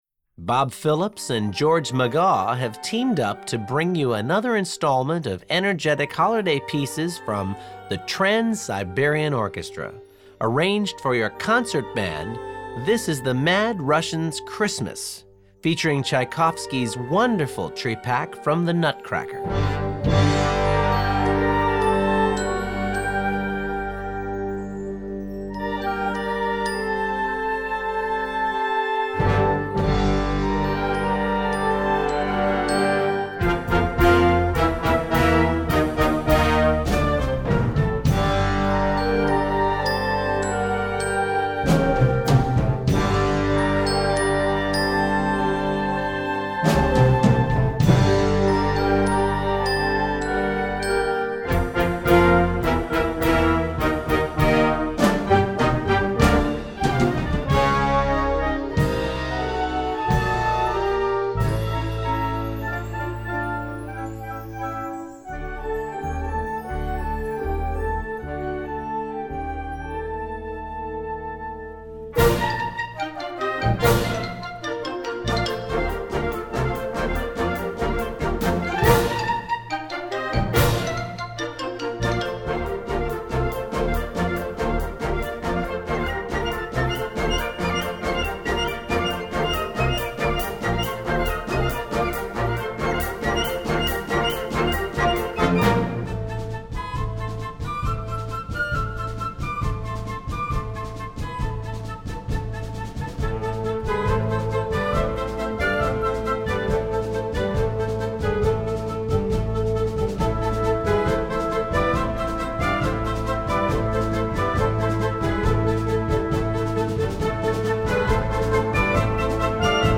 Gattung: Weihnachtliche Blasmusik
Besetzung: Blasorchester